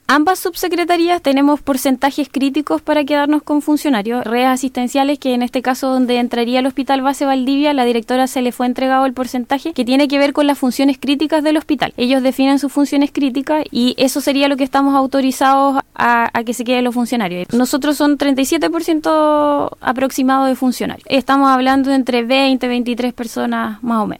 En entrevista con La Radio la seremi de Salud en Los Ríos, Cristina Ojeda, indicó que cuentan con un determinado porcentaje para dar continuidad a los funcionarios.